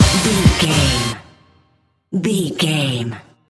Fast paced
Ionian/Major
synthesiser
drum machine
Eurodance